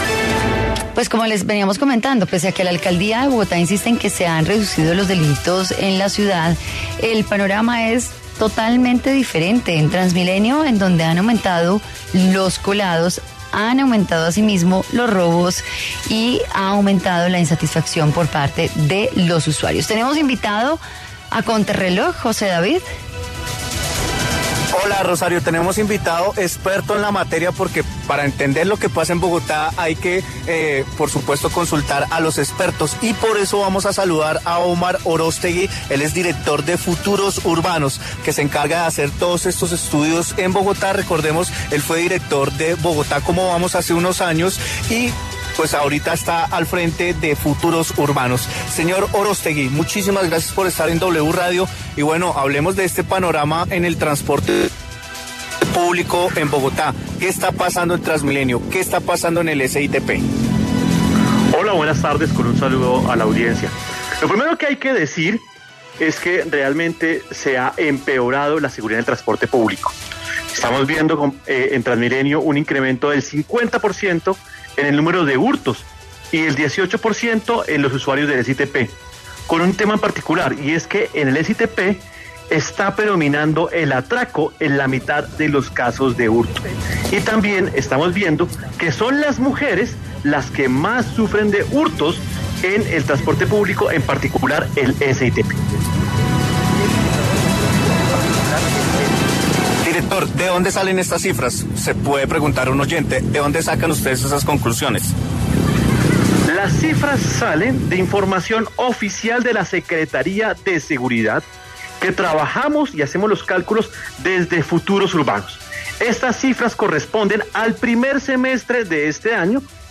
Se disparó la inseguridad en TransMilenio: Robos incrementaron 50%, según cifras | Contrarreloj | WRadio